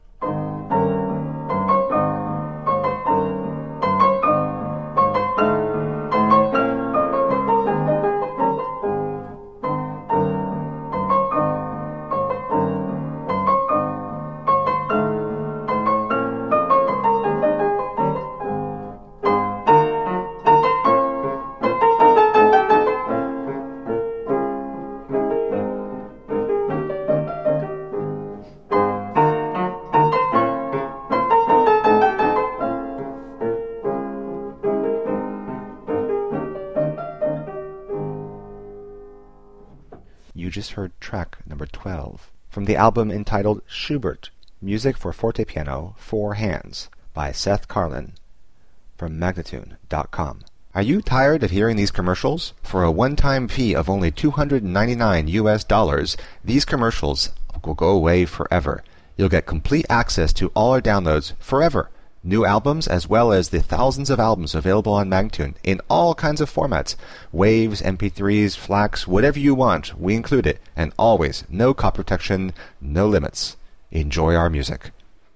Fortepiano music from the early 1800s.